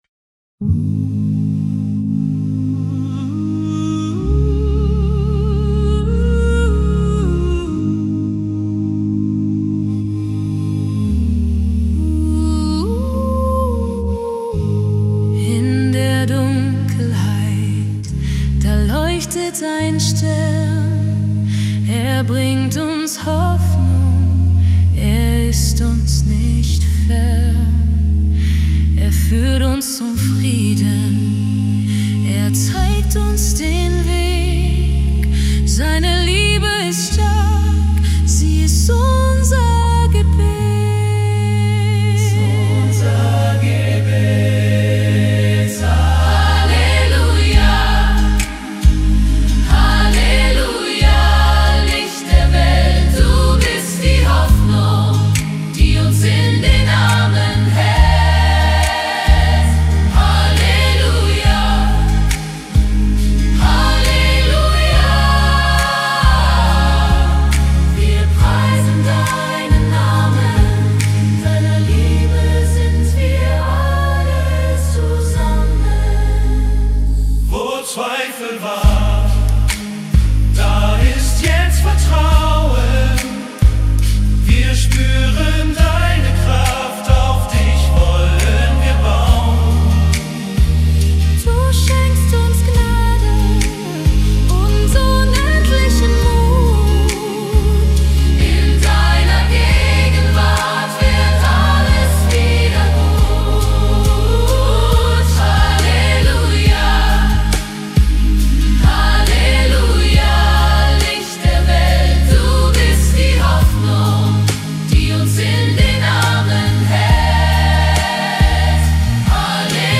Gospelsong
Mein aktuelles Projekt ist ein Gospel-Song,